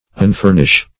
Search Result for " unfurnish" : The Collaborative International Dictionary of English v.0.48: Unfurnish \Un*fur"nish\, v. t. [1st pref. un- + furnish.] To strip of furniture; to divest; to strip.